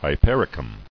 [hy·per·i·cum]